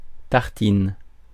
Ääntäminen
Ääntäminen France (Île-de-France): IPA: /taʁ.tin/ Paris: IPA: [taʁ.tin] Haettu sana löytyi näillä lähdekielillä: ranska Käännös Substantiivit 1. ŝmirpano Suku: f .